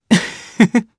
Arch-Vox_Happy1_jp.wav